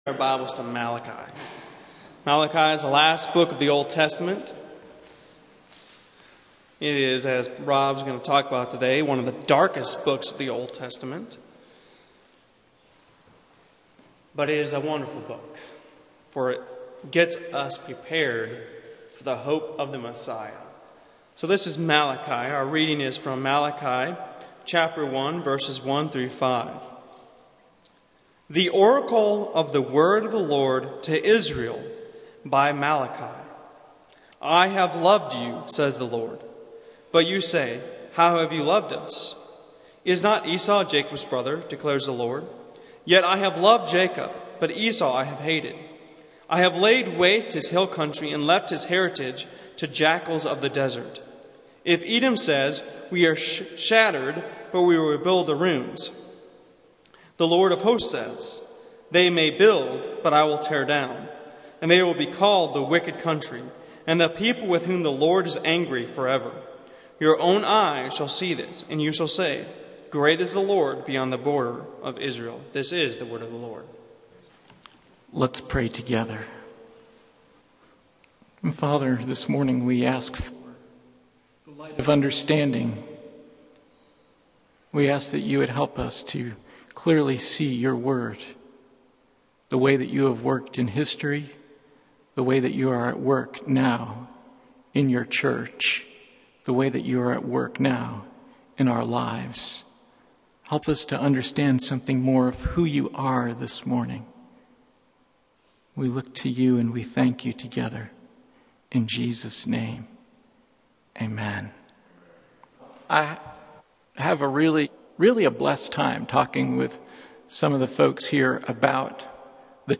Passage: Malachi 1:1-5 Service Type: Sunday Morning